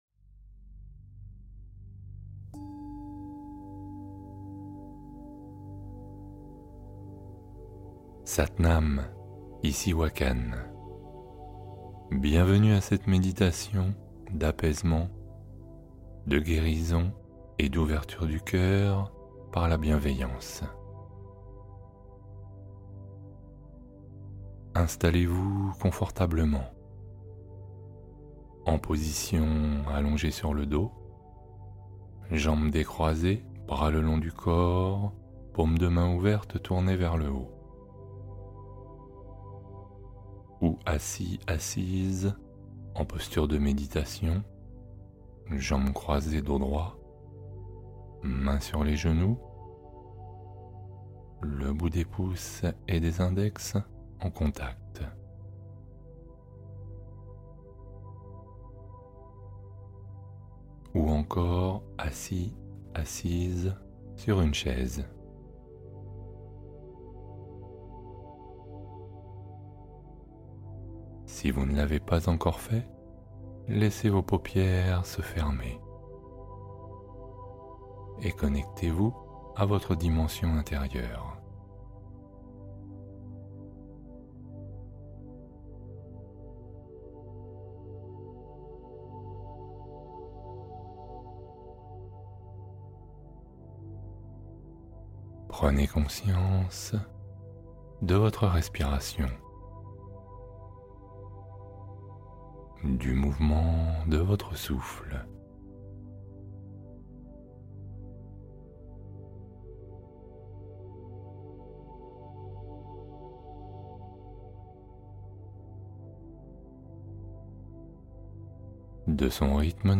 Ouvrez votre cœur : méditation de guérison, apaisement et bienveillance profonde
Méditation Guidée: Le podcast